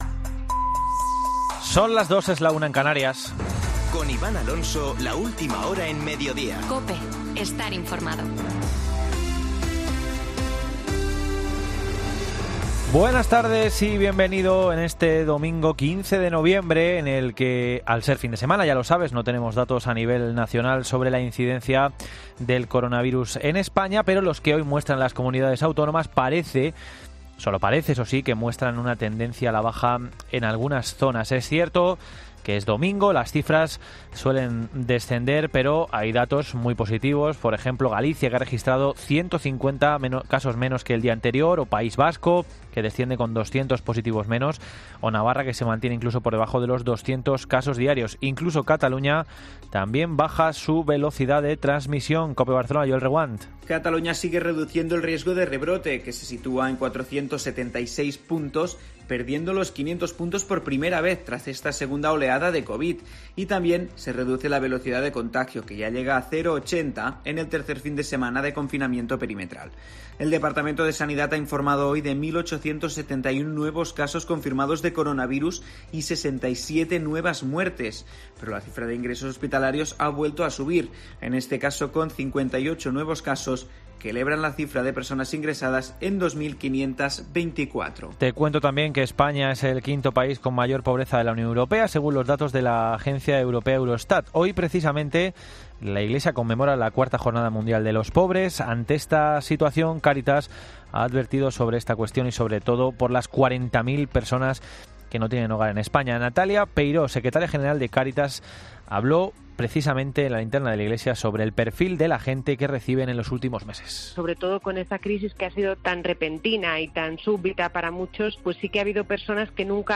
Boletín de noticias de COPE del 15 de noviembre de 2020 a las 14.00 horas